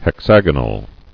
[hex·ag·o·nal]